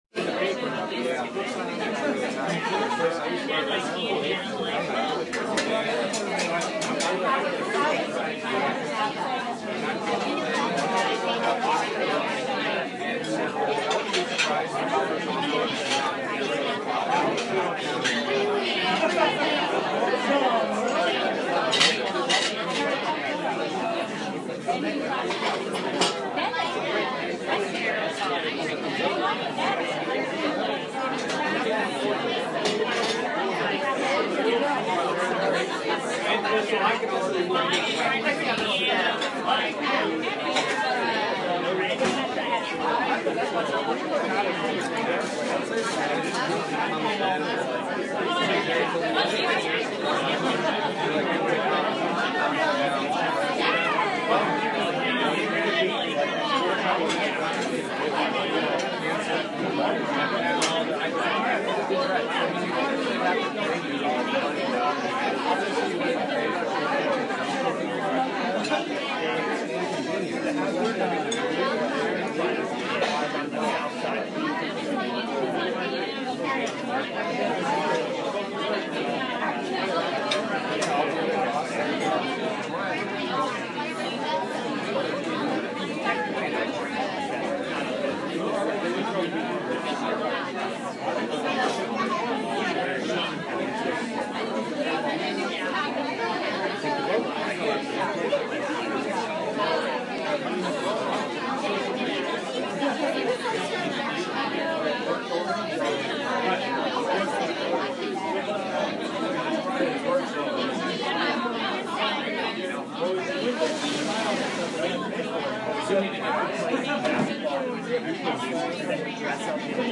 freesound_community-restaurant-ambience-24720.mp3